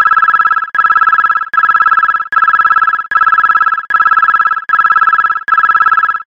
Category: HTC Ringtones